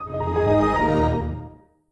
Windows X2 Startup.wav